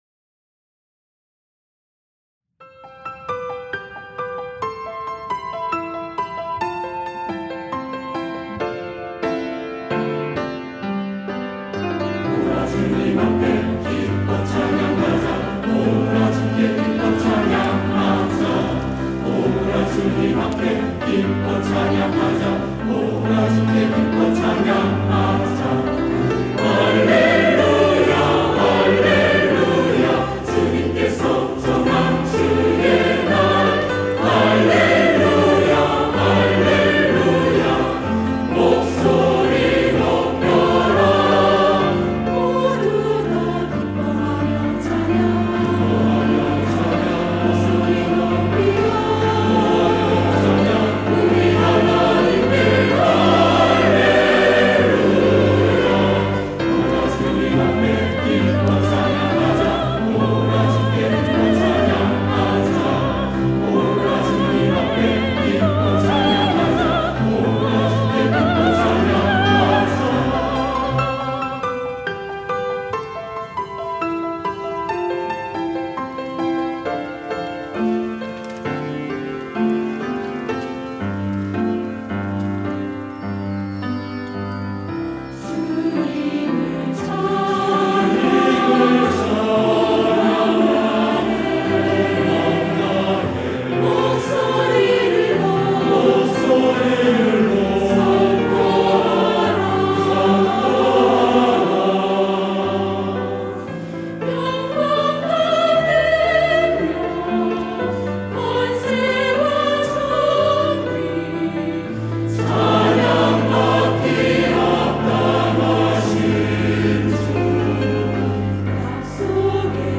할렐루야(주일2부) - 오라 기뻐 찬양하자
찬양대